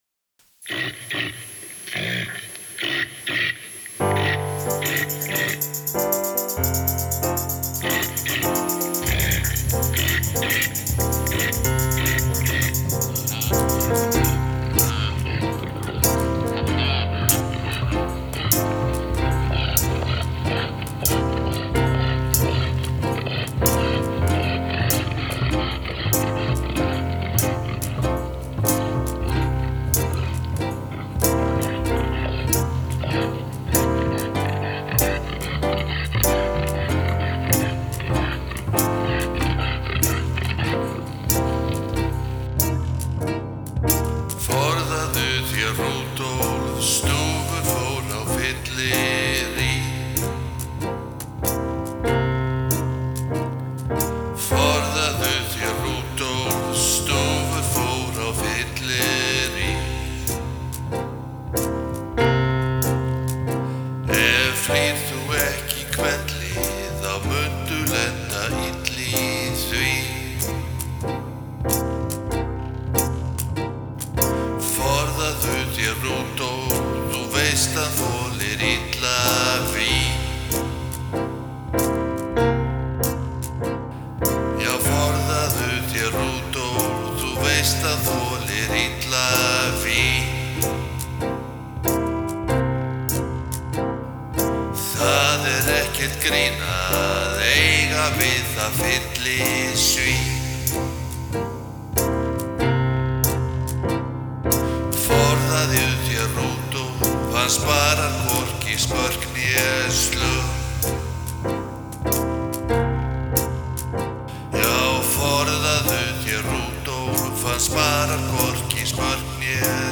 Söngur